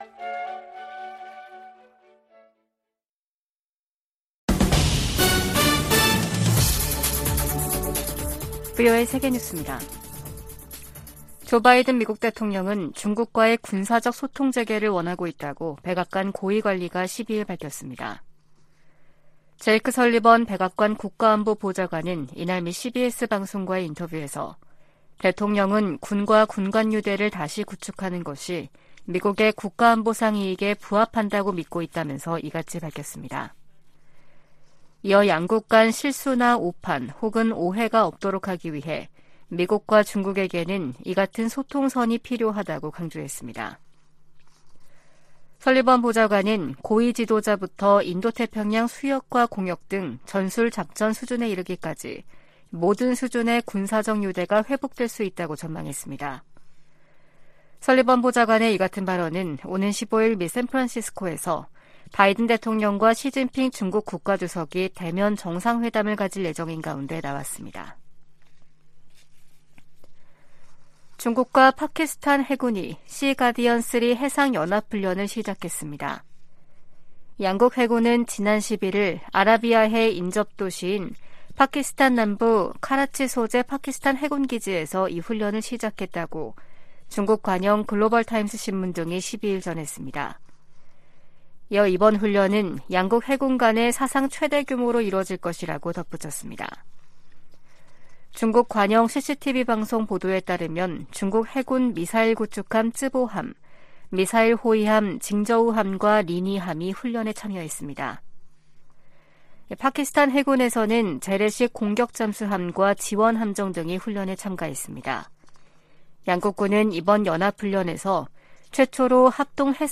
VOA 한국어 아침 뉴스 프로그램 '워싱턴 뉴스 광장' 2023년 11월 14일 방송입니다. 미국과 한국은 북한의 핵 위협에 대응해 맞춤형 억제전략(TDS)을 10년만에 개정하고, 미군 조기경보위성 정보 공유를 강화하기로 했습니다. 두 나라는 또 사이버안보 분야의 협력 강화를 위한 업무협약을 맺었습니다.